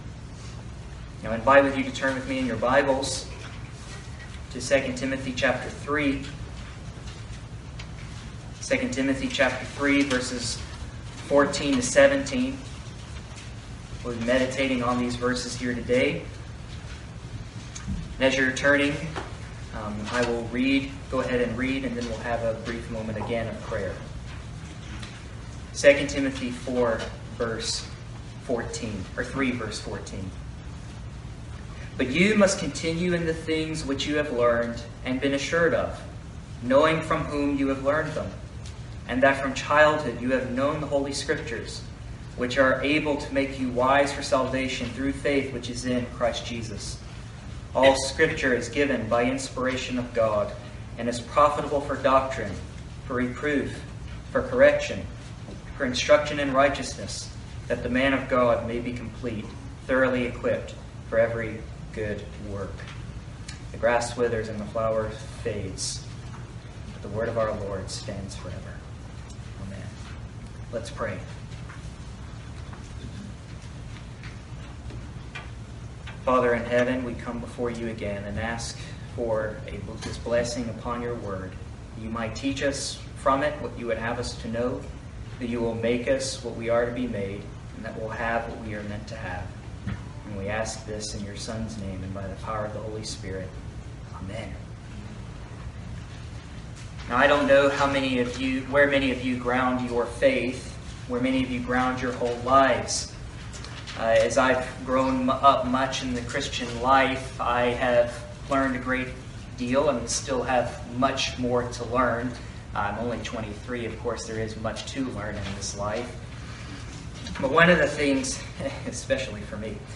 In this sermon, we look at the Bible and its sufficiency for the Christian life.
Service Type: Sunday Morning